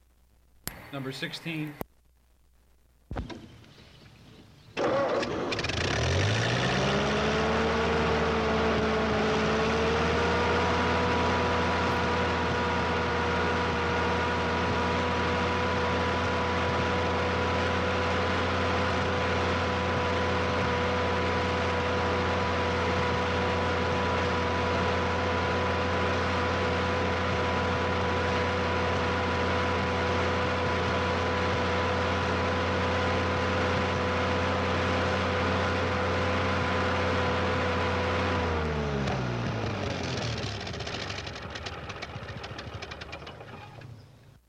老式卡车 " G1416 老式卡车的怠速溅射问题
描述：卡车门，发动机转速和嘈杂的启动，并以大声和快速的速度闲置。发动机溅到了停止位置。 这些是20世纪30年代和20世纪30年代原始硝酸盐光学好莱坞声音效果的高质量副本。 40年代，在20世纪70年代早期转移到全轨磁带。我已将它们数字化以便保存，但它们尚未恢复并且有一些噪音。
Tag: 卡车 交通运输 光学 经典